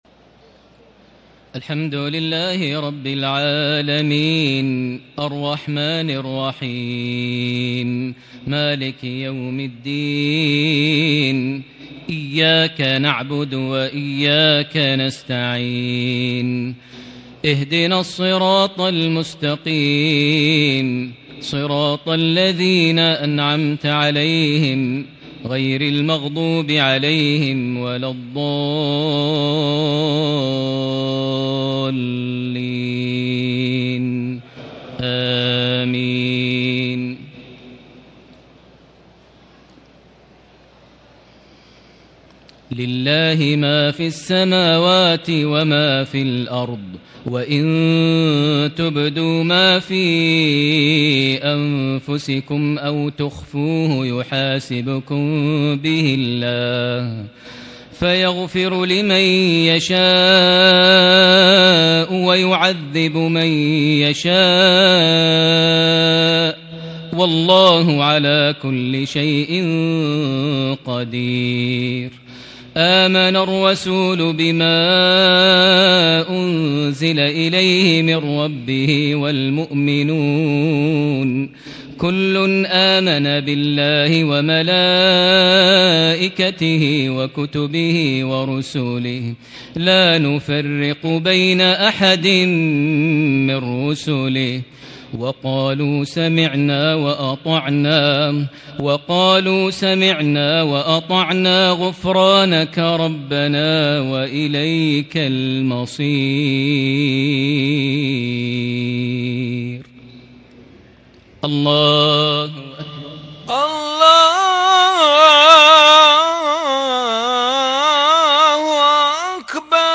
صلاة العشاء ١١ رمضان ١٤٤٠هـ خواتيم سورة البقرة (284-286) > 1440 هـ > الفروض - تلاوات ماهر المعيقلي